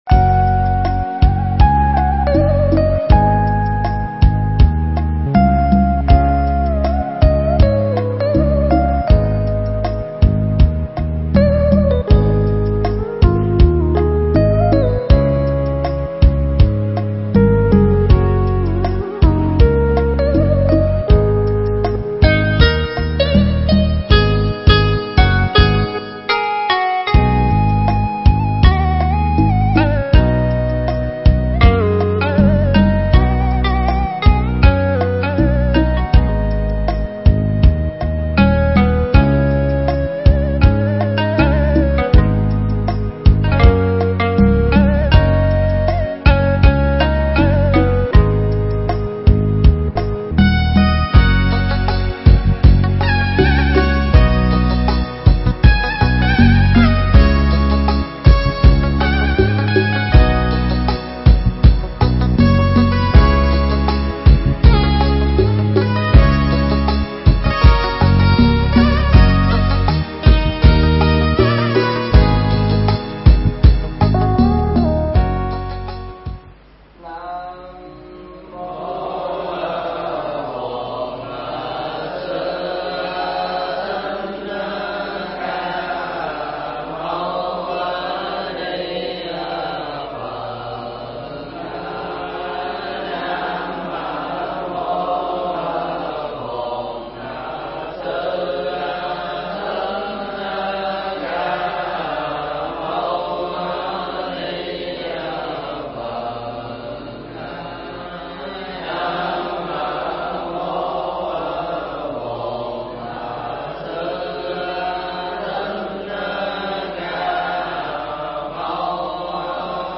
Mp3 Pháp Thoại Kinh Di Giáo 18
Giảng tại Tu Viện Tường Vân (Bình Chánh)